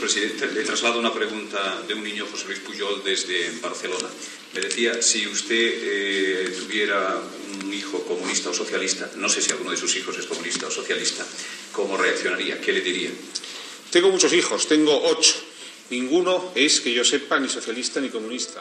Fragment d'una entrevista al president del Govern espanyol, Leopoldo Calvo Sotelo.
Info-entreteniment